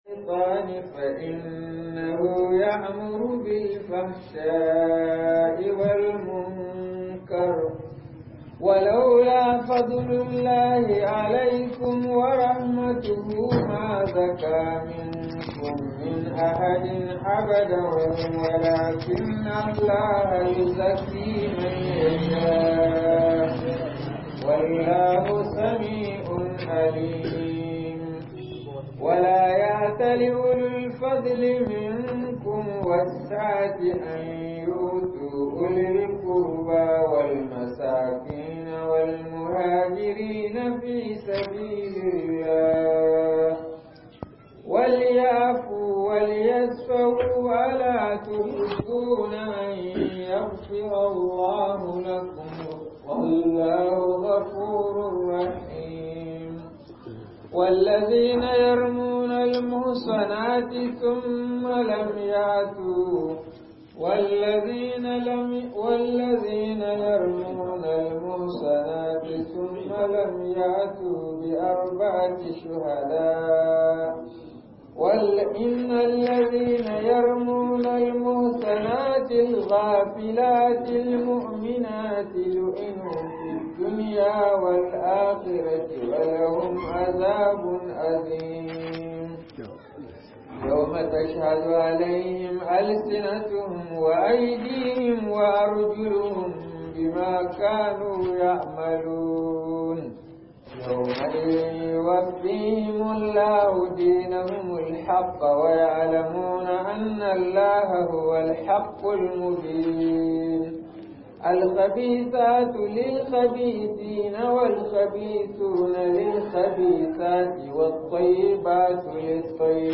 آداب معاشرة بين الزوجين - MUHADARA